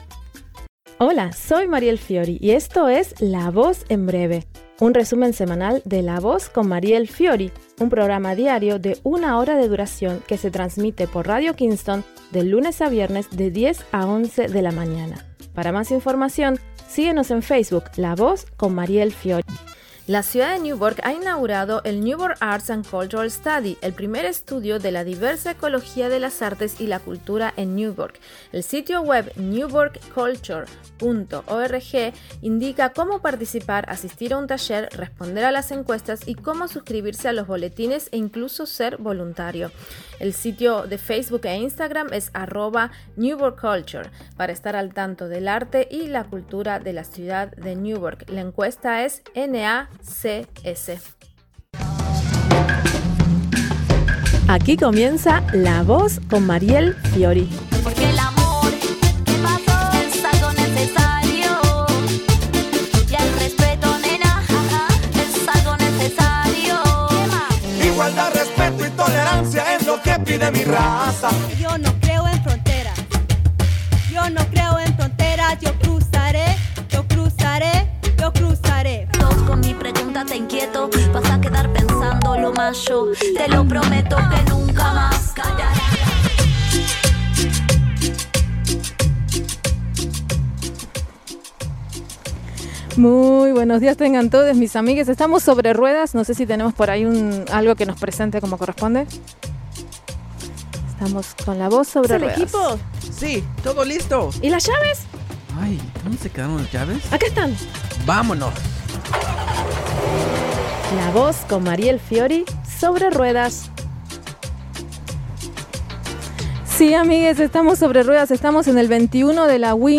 She had her first live remote broadcast program in six months. Rain and all they went to the Kingston City School District on National Day of Action to support children and demand safe schools.